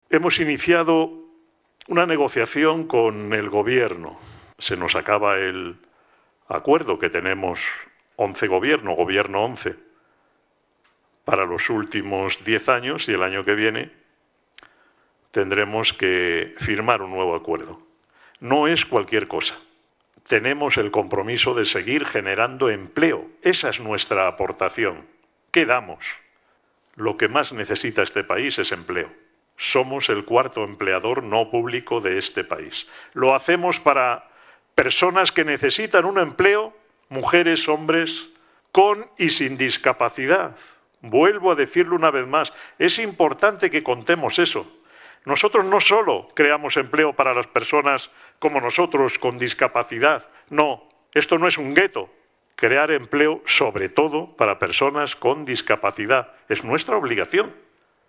Un llamamiento que hizo el último Comité de Coordinación General (CCG) organizado por el Consejo General de la Organización los pasados 26 y 27 de noviembre, desde la sede de Fundación ONCE, ante un restringido grupo de asistentes presenciales debido al Covid, pero que conectó online a casi 160 personas de todos los puntos de la geografía española.